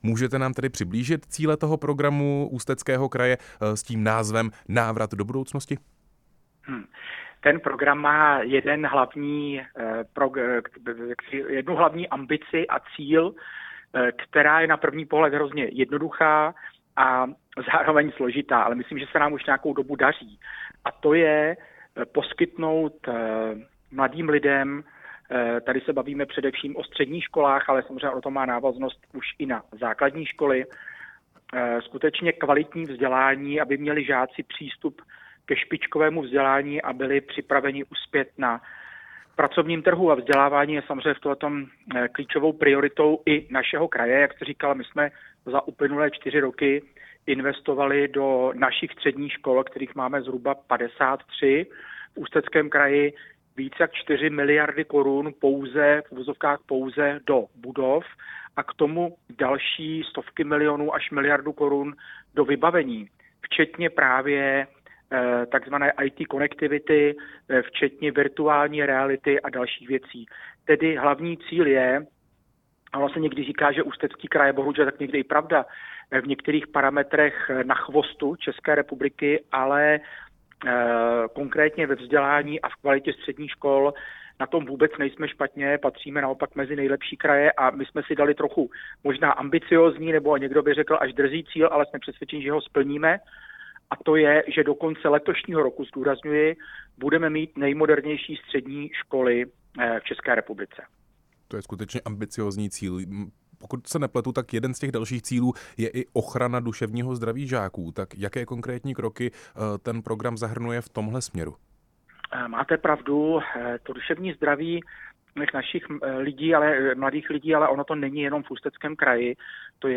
Rozhovor s hejtmanem Ústeckého kraje Richardem Brabcem